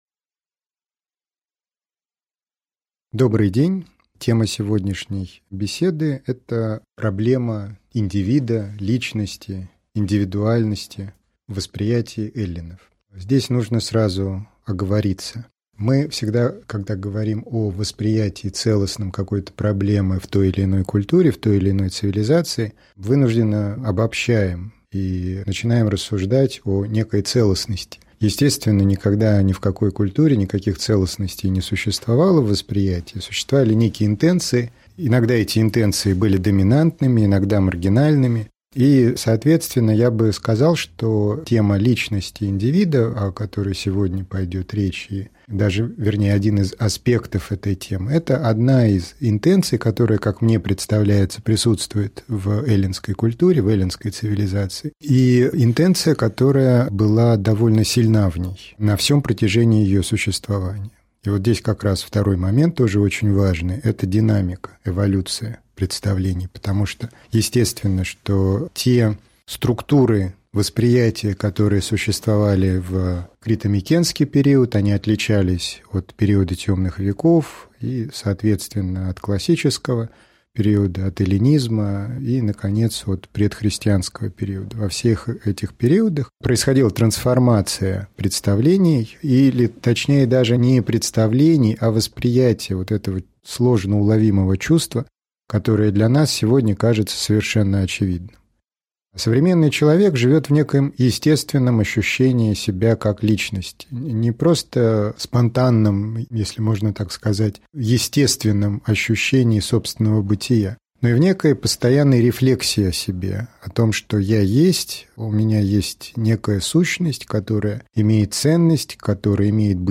Аудиокнига Лекция «Индивидуум» | Библиотека аудиокниг